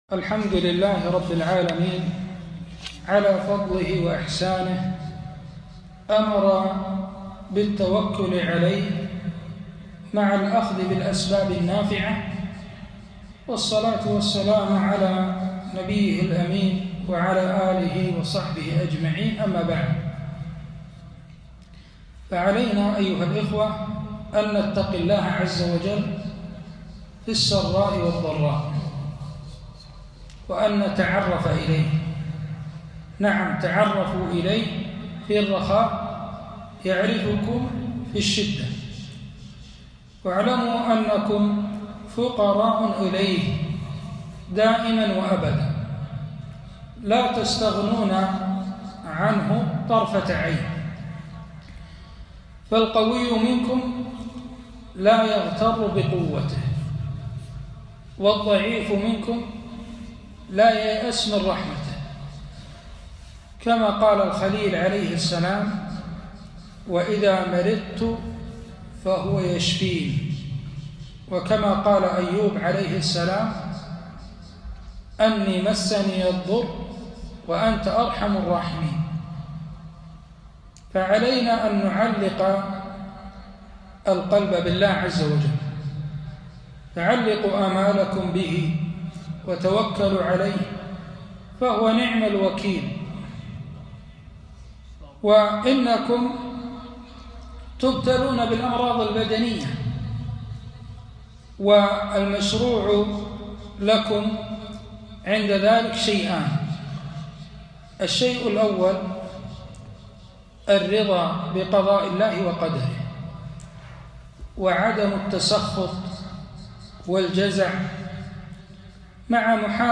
كلمة - أحكام التداوي والدواء